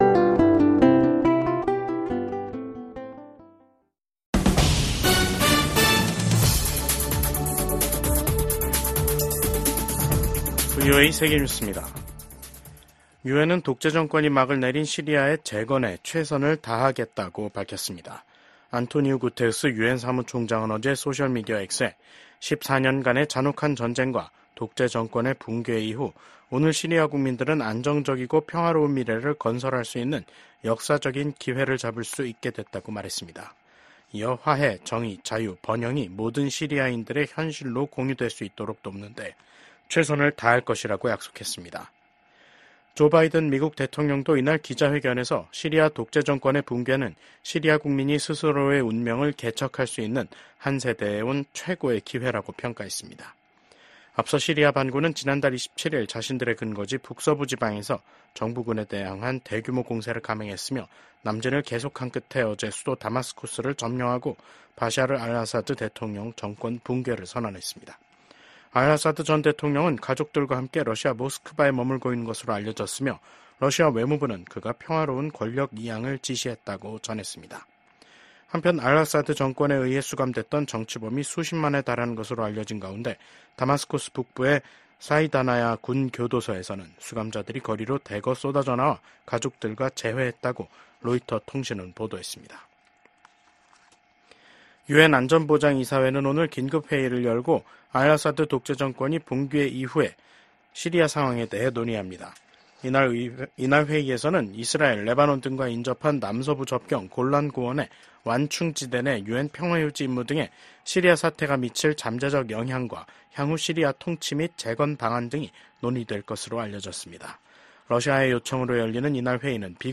VOA 한국어 간판 뉴스 프로그램 '뉴스 투데이', 2024년 12월 9일 2부 방송입니다.